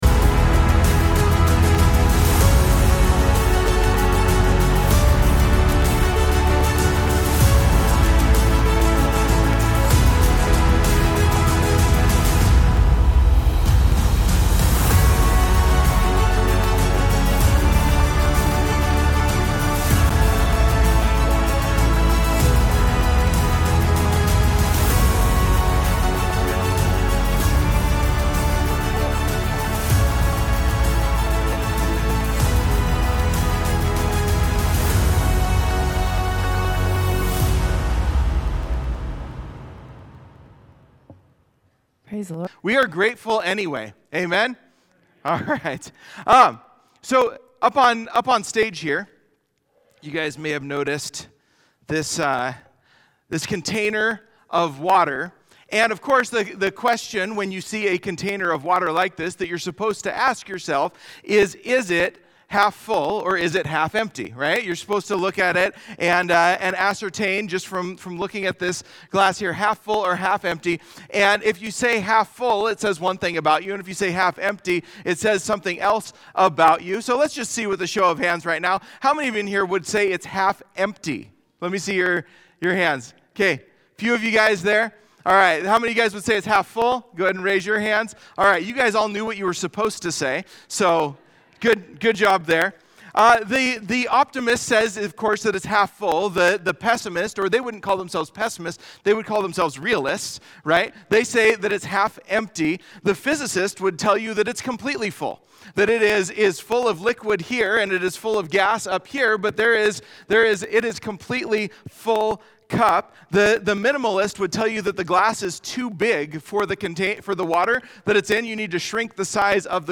11-23-Sermon-MP3.mp3